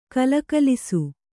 ♪ kalakalisu